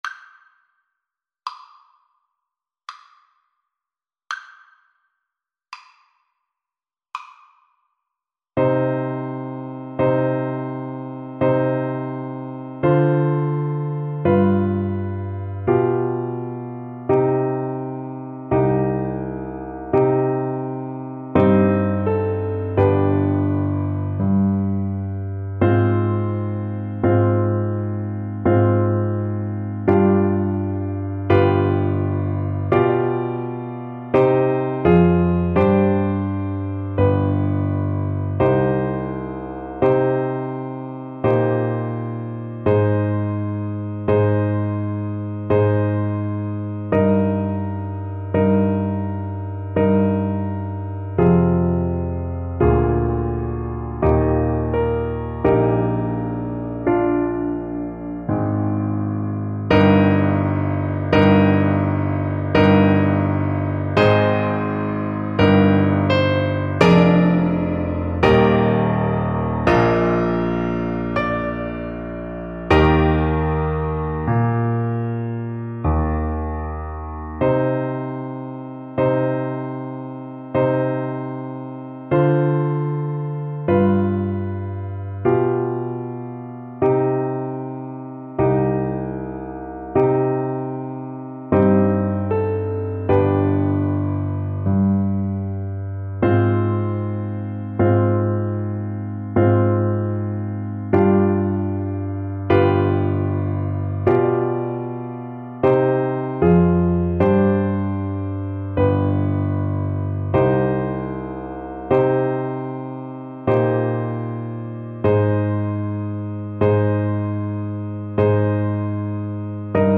Play (or use space bar on your keyboard) Pause Music Playalong - Piano Accompaniment Playalong Band Accompaniment not yet available transpose reset tempo print settings full screen
Trumpet
C minor (Sounding Pitch) D minor (Trumpet in Bb) (View more C minor Music for Trumpet )
Largo con espressione =66
3/4 (View more 3/4 Music)
Classical (View more Classical Trumpet Music)
tartini_sarabanda_TPT_kar3.mp3